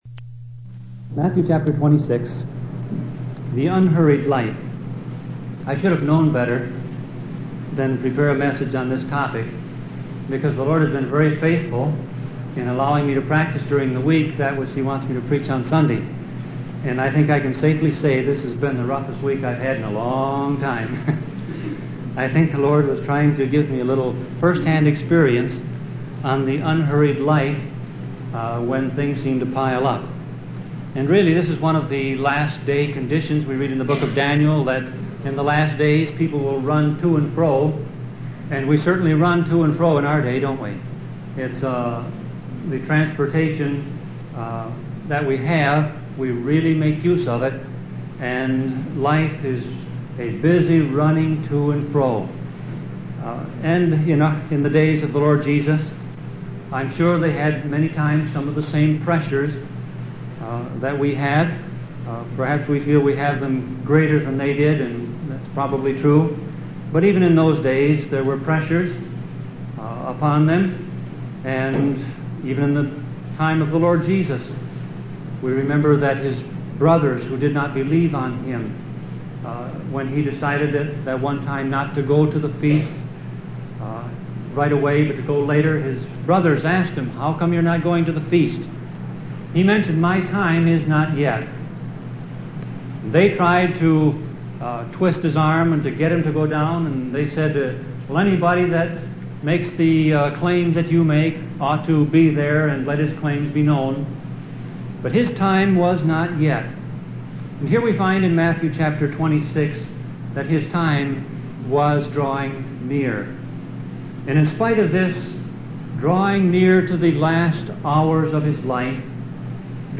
Sermon Audio Passage: Matthew 26:1-30 Service Type